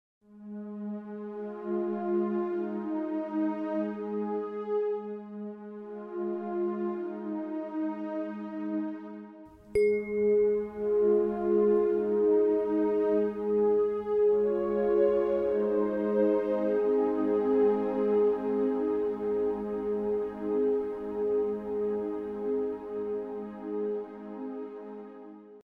Sie hören jeweils eine begleitende auf die jeweilige Frequenz abgestimmte Tonfolge und die Klangröhre, die exakt in der entsprechenden Frequenz schwingt.
417 Hz (Resonanz, Veränderungen) - REsonare fibrisKomposition "Veränderung"
417 Hz I.mp3